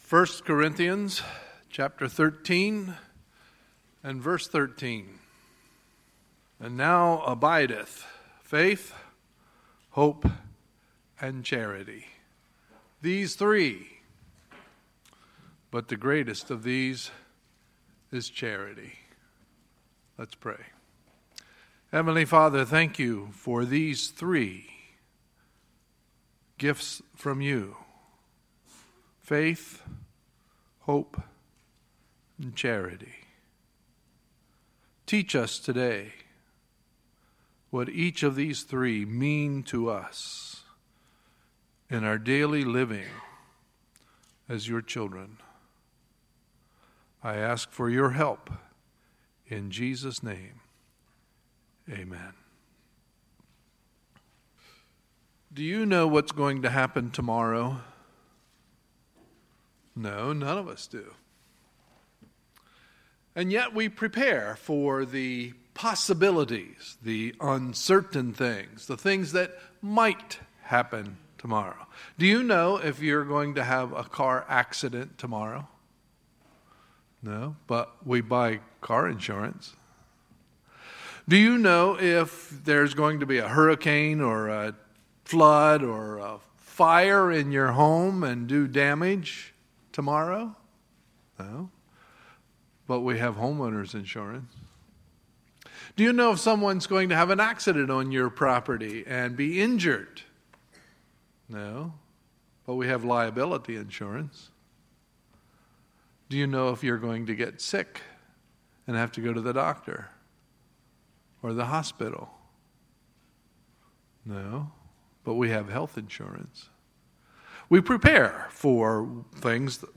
Sunday, June 11, 2017 – Sunday Morning Service
Sermons